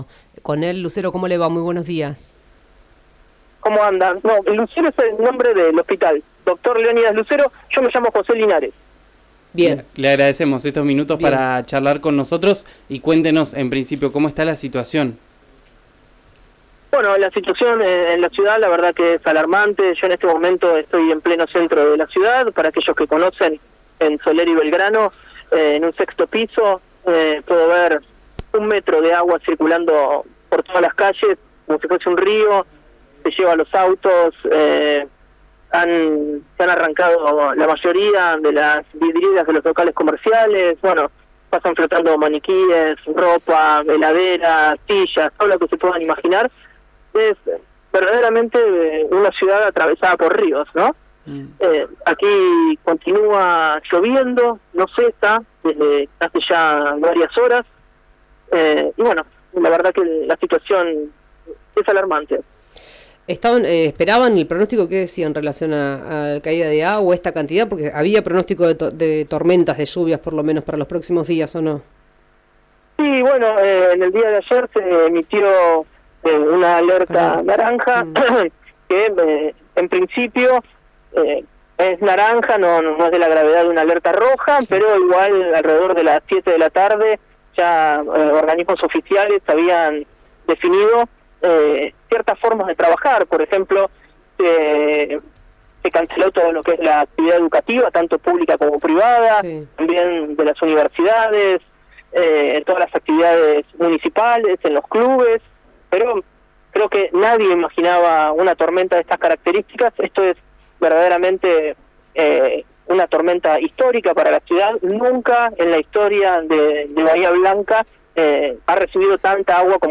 Un periodista de la ciudad relató la conmoción que se vive tras las fuertes lluvias.